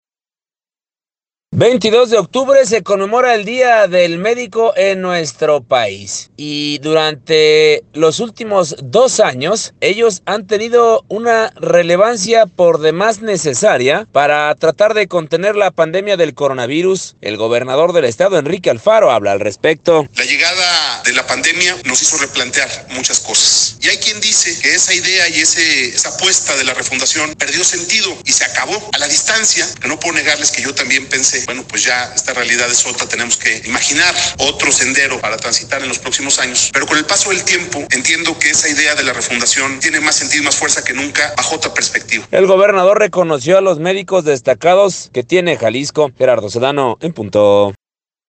El gobernador del estado, Enrique Alfaro, habla al respecto: